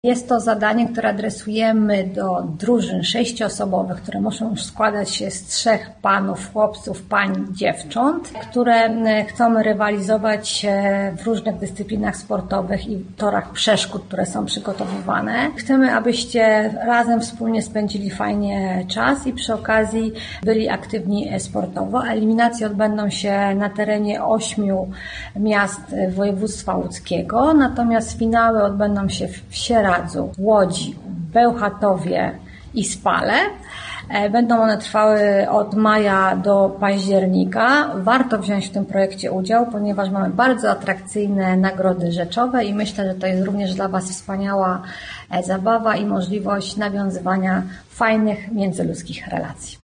Tłumaczy Joanna Skrzydlewska, marszałek województwa łódzkiego: